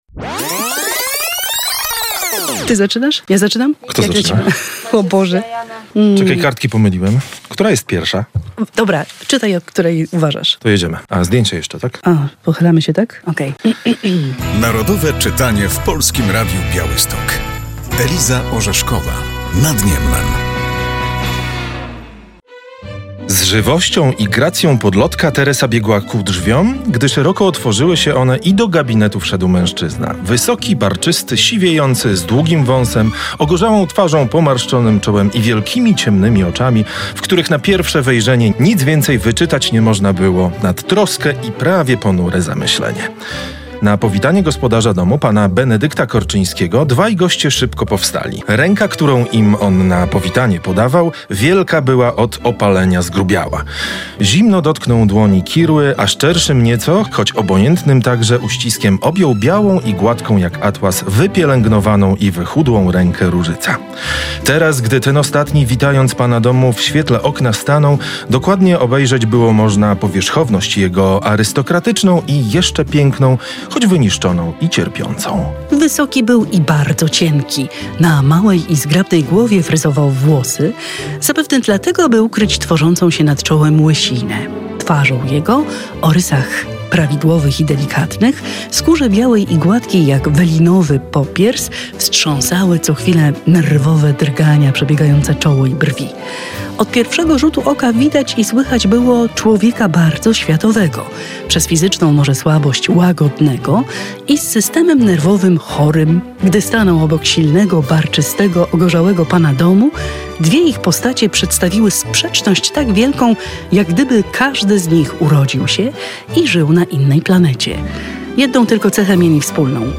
Pozytywistyczną powieść przybliża w tym roku ogólnopolska akcja Narodowe Czytanie. Włączają się do niej także dziennikarze Polskiego Radia Białystok.